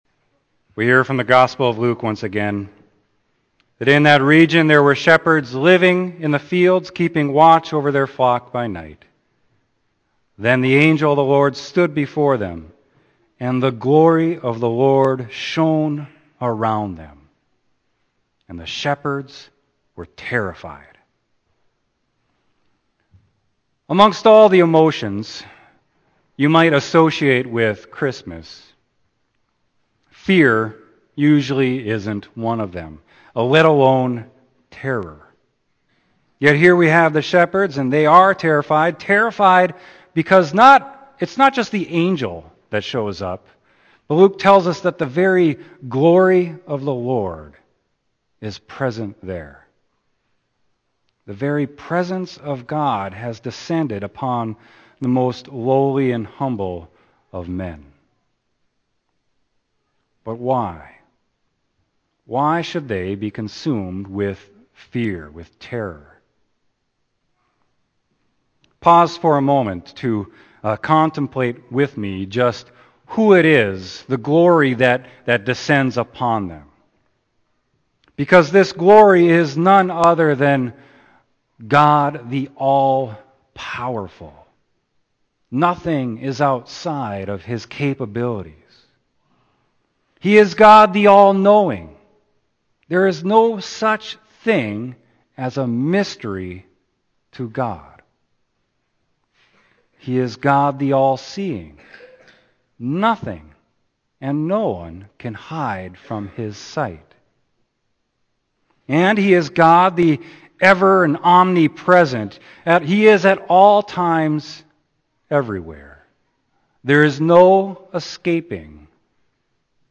Scriptures: Isaiah 9:2-7; Luke 1:26-35; 46-55; 2:1-20 Sermon: Luke 2.8-14